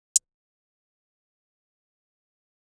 hihat 9.wav